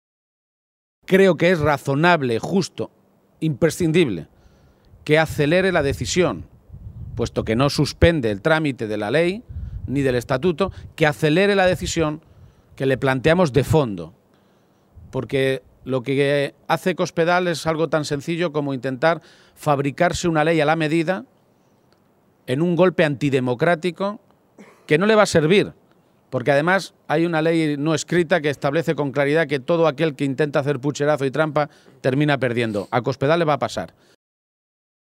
García-Page se pronunciaba de esta manera esta mañana, en Toledo, en una comparecencia ante los medios de comunicación durante la reunión que ha dirigido junto al secretario de organización federal del PSOE, César Luena.